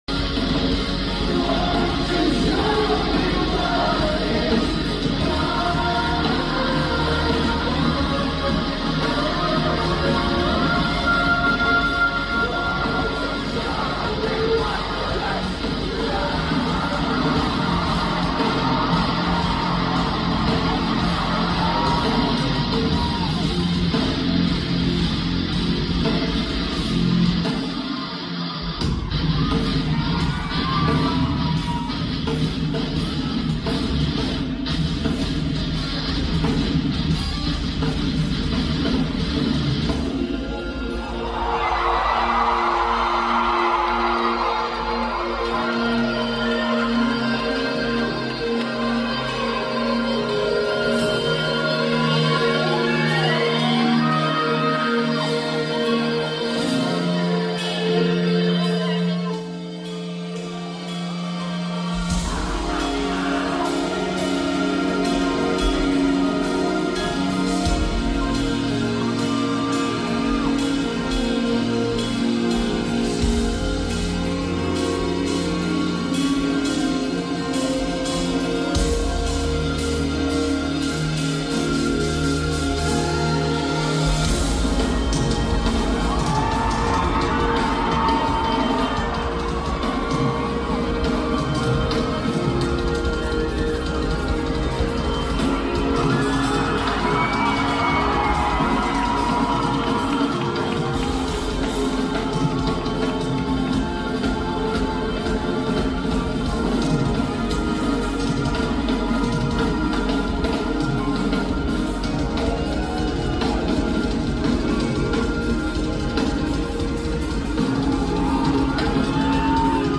North American tour. Toronto night 2.